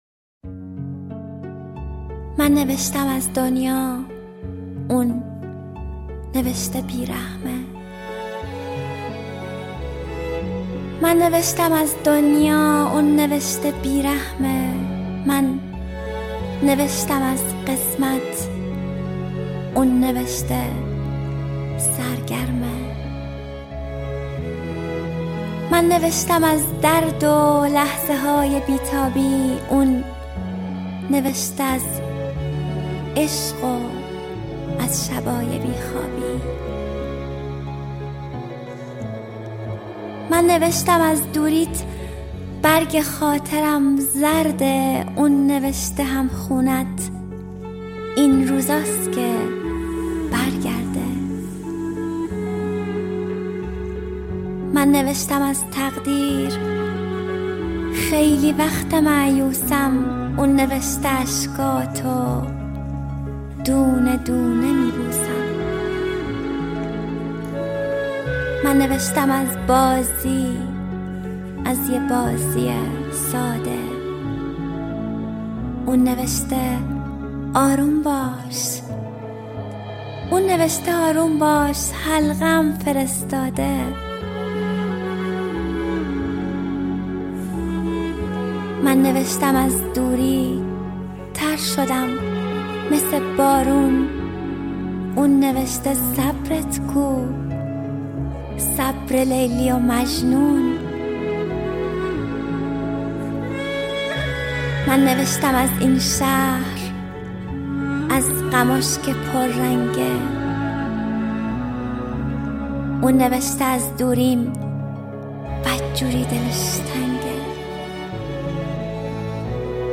دانلود دکلمه مریم حیدرزاده به نام بارون میاد